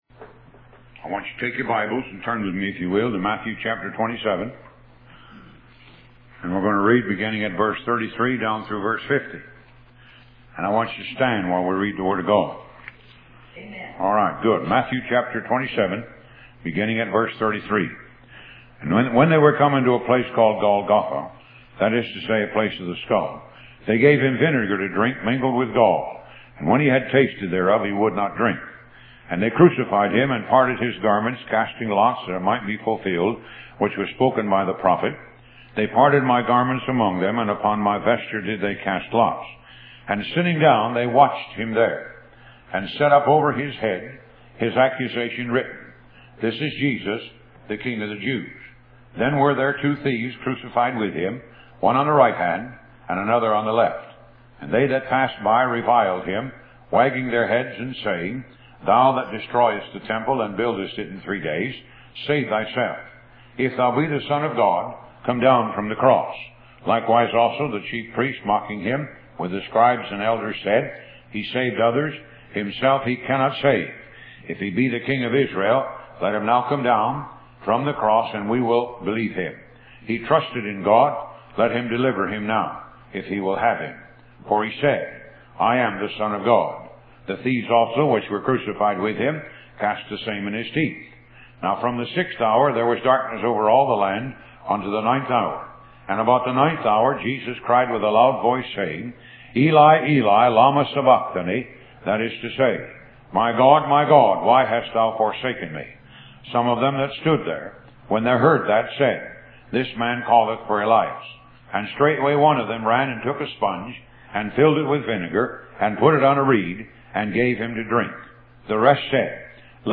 Upon his passing, the Ministry has continued the radio broadcast on some radio stations and through various social media sites.